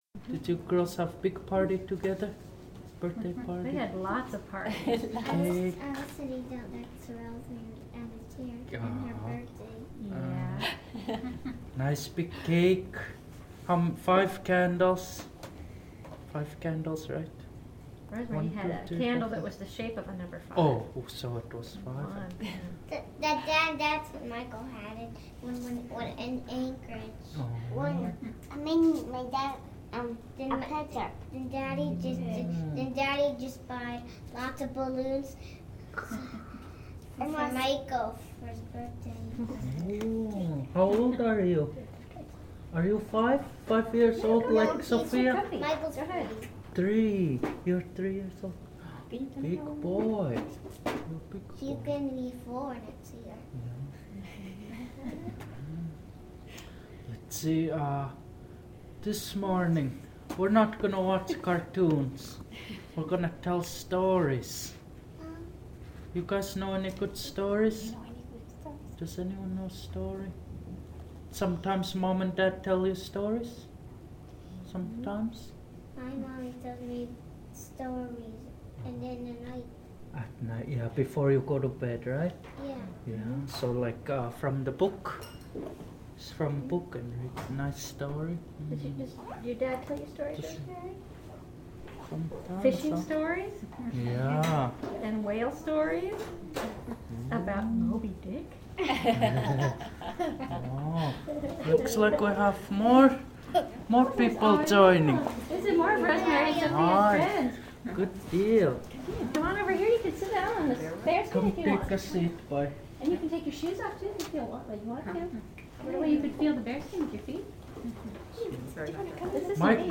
sharing White Squirrel story with youth
Kodiak, Alaska